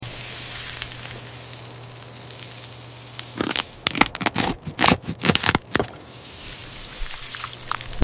One ASMR explosion.